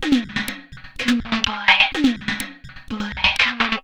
Index of /90_sSampleCDs/Sample Magic - Transmission-X/Transmission-X/transx loops - 125bpm